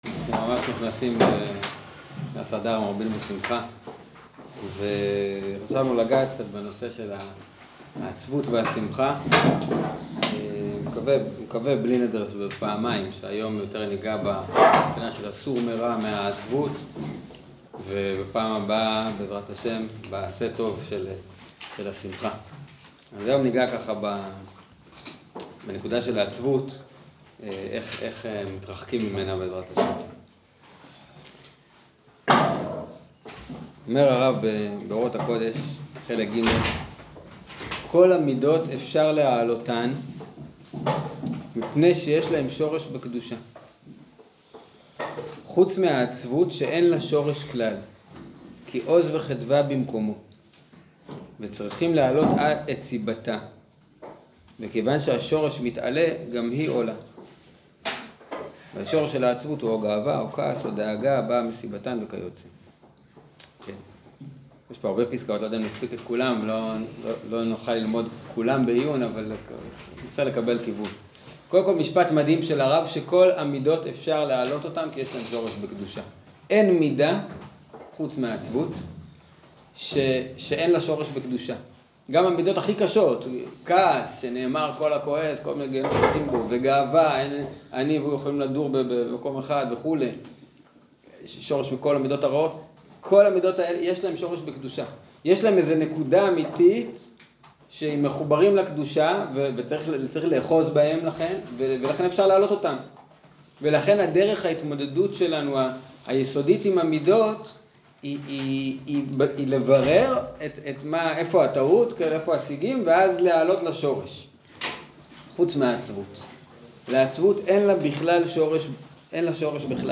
שיעור בעניין איך לא ליפול אל העצבות שבשונה משאר המידות הרעות היא מידה המנותקת מהקב"ה ותיקונה הוא חיבור חזרה אל הקב"ה